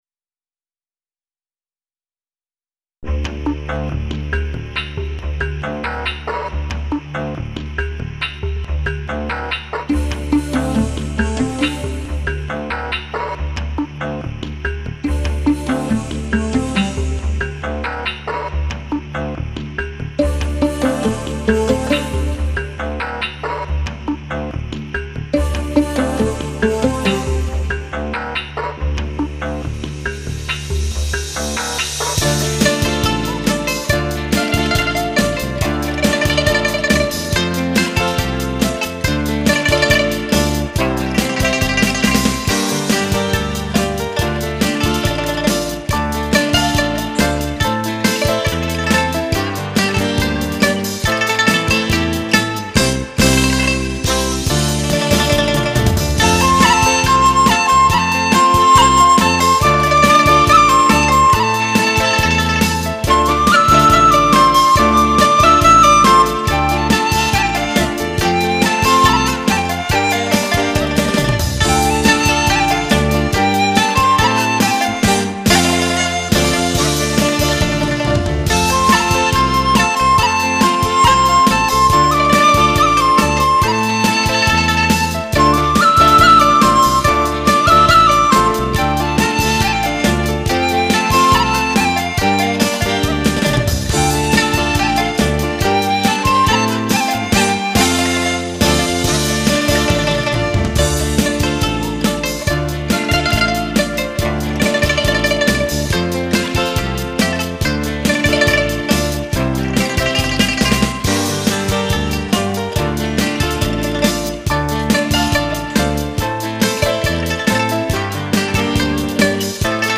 中国各地的民歌风格各异，一般北方的高亢，南方的舒缓。
重庆秀山民歌